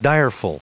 Prononciation du mot direful en anglais (fichier audio)
Prononciation du mot : direful